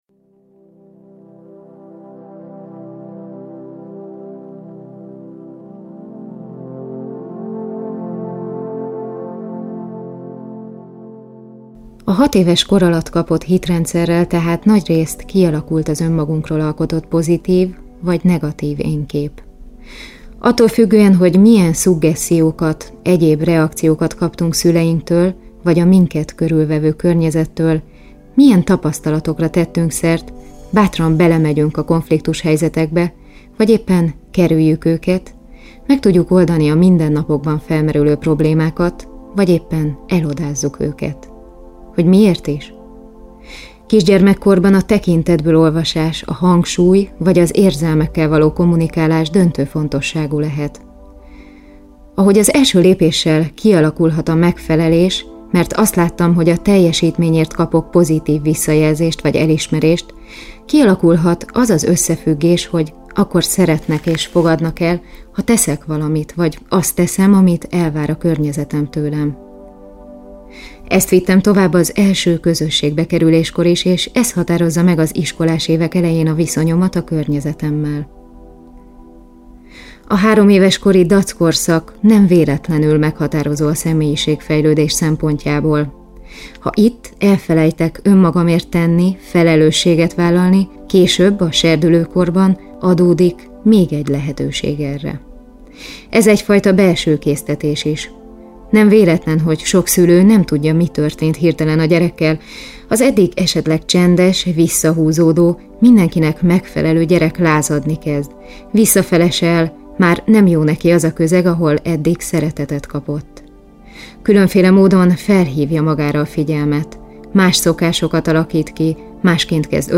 Mindegyik cd-n Szepes Mária előszava hallható! fenyhaz